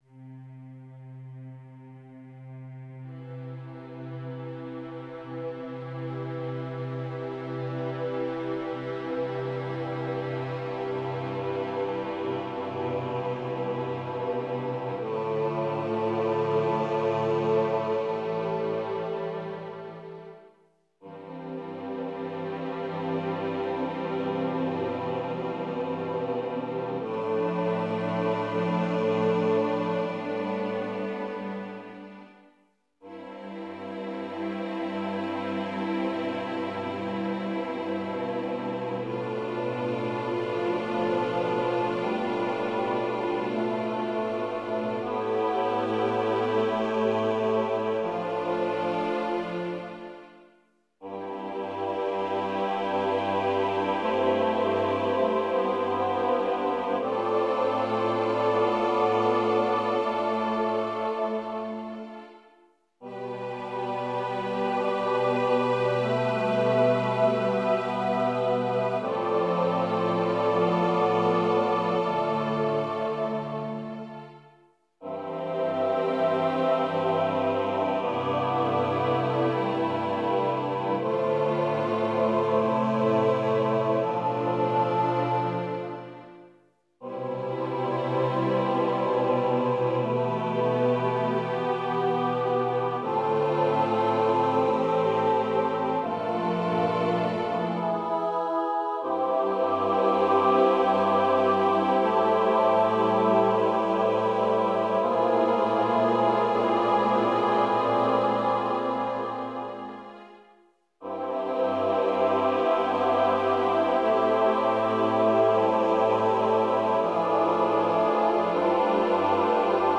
Voicing/Instrumentation: SSATTBB , Viola